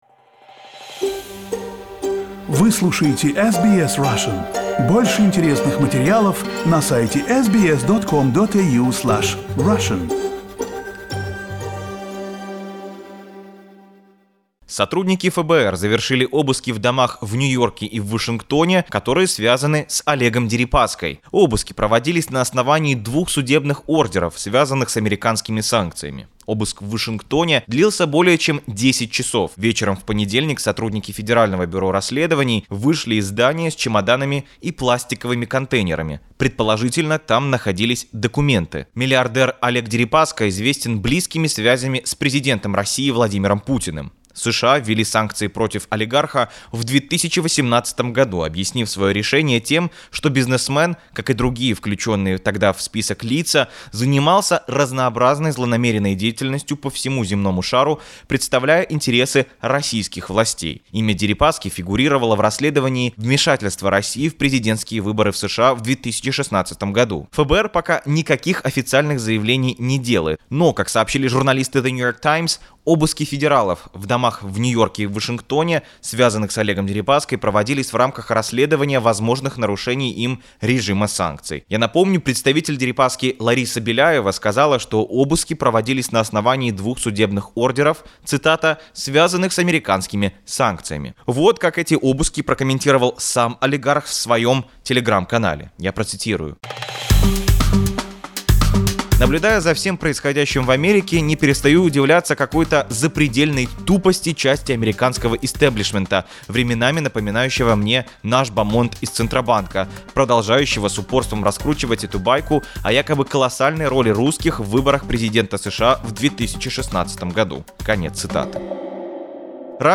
The businessman has been under US sanctions since 2018. Hear the details from the scene.